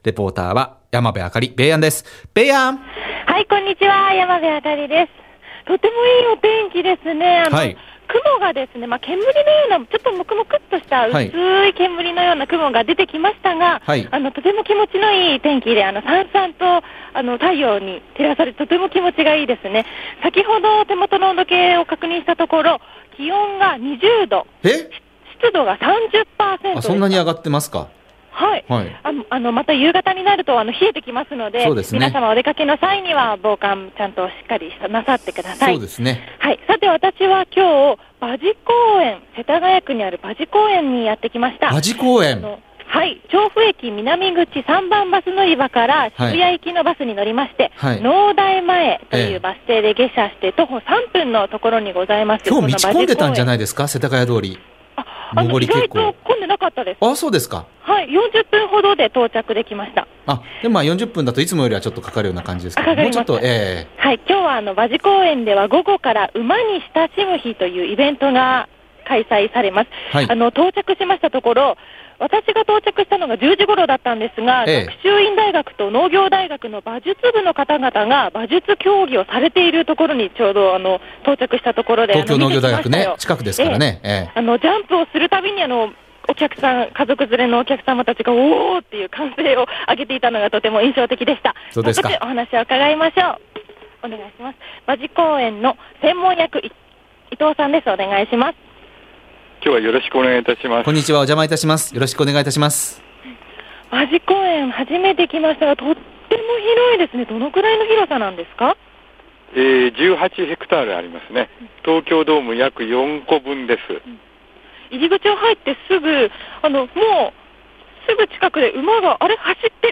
調布駅南口３番バス乗り場から渋谷行きのバスに乗って、「農大前」のバス停で下車して３分のところにある 馬事公苑へお邪魔しました。 入り口を入ってすぐに、パカッパカッパカッ！！！馬の走る足音が聞こえました！